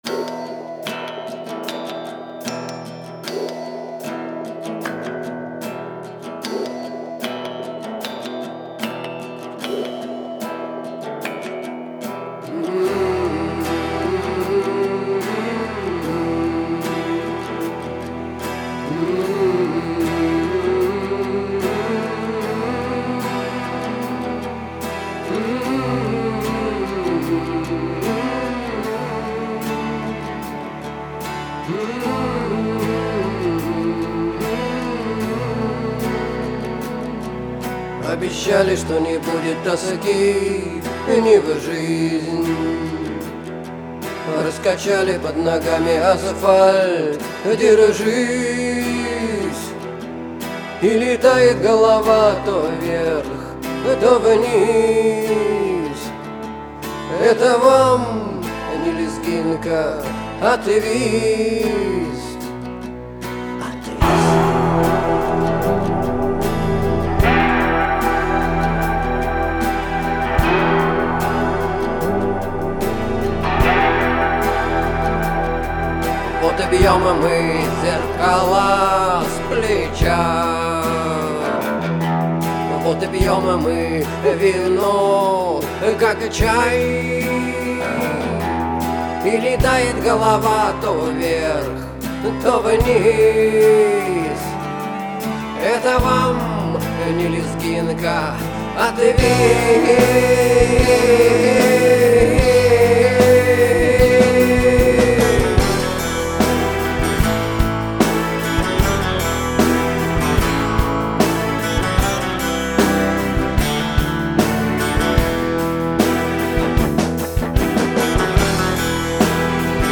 Стиль: Rock
Тэги: Art Rock